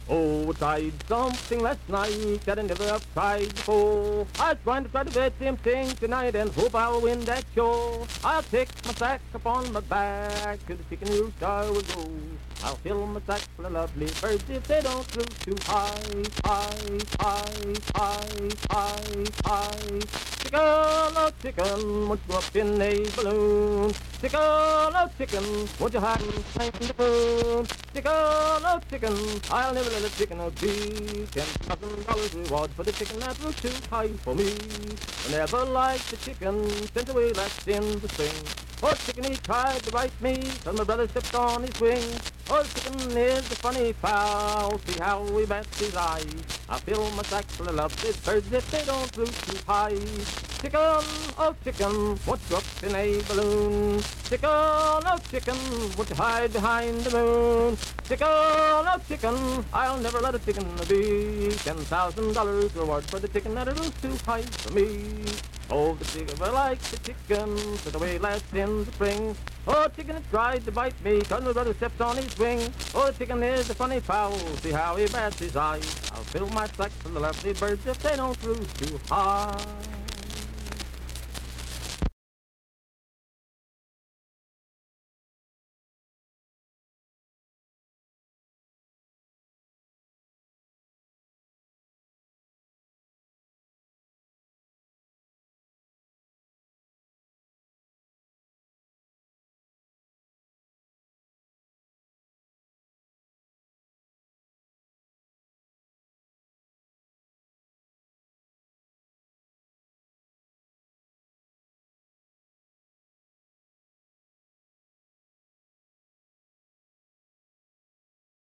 Unaccompanied vocal performance
Minstrel, Blackface, and African-American Songs, Thieves and Thefts
Voice (sung)
Vienna (W. Va.), Wood County (W. Va.)